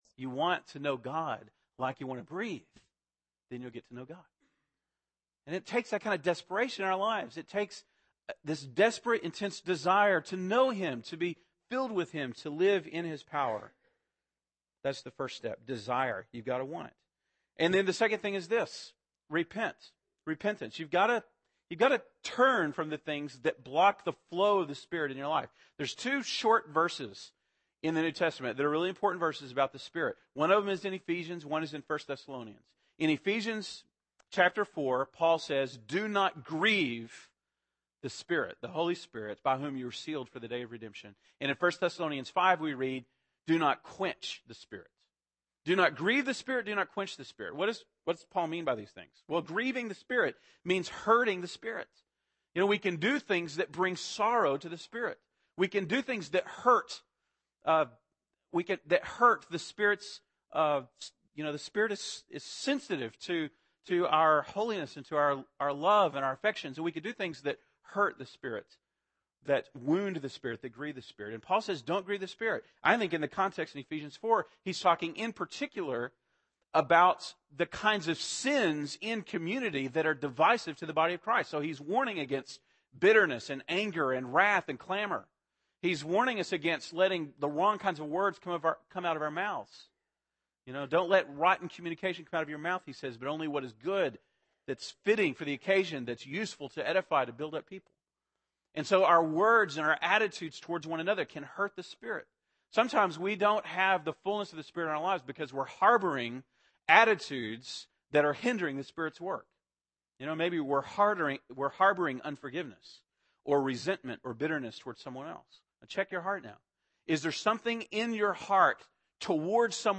February 20, 2011 (Sunday Morning)